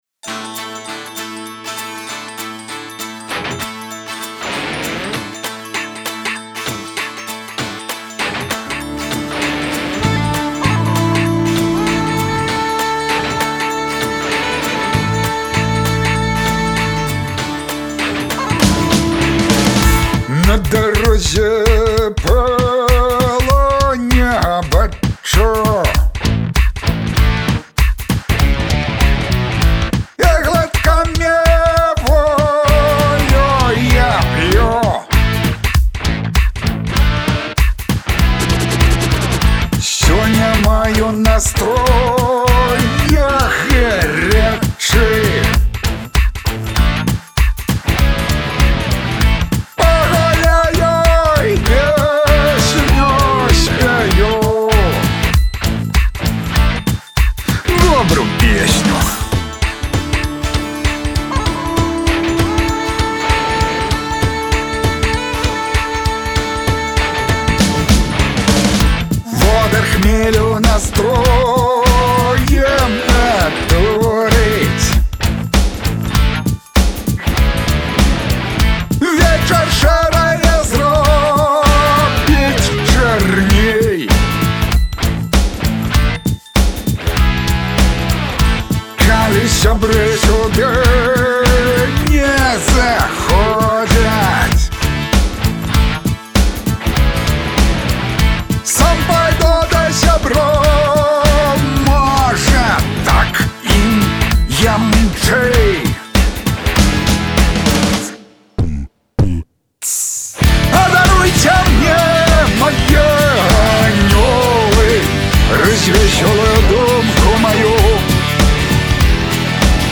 пазытыўны твор